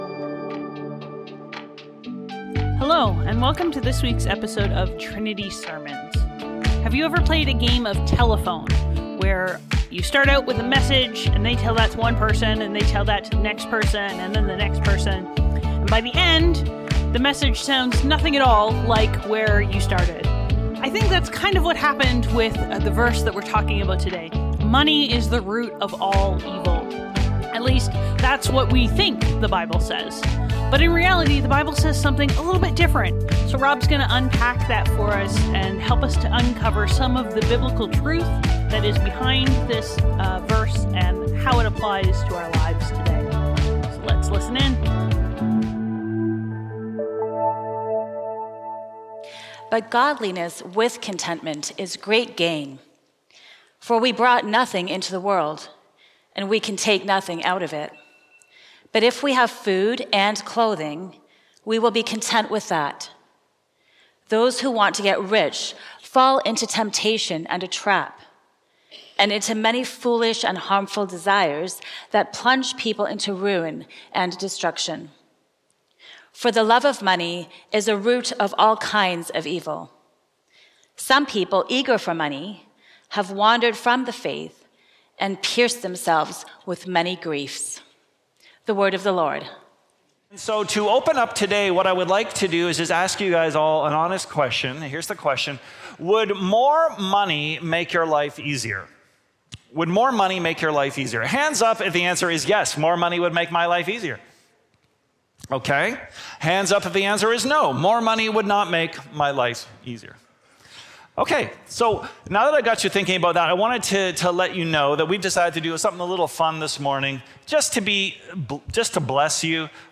Trinity Streetsville - Money is the Root of All Evil | The Bible Doesn't Say That | Trinity Sermons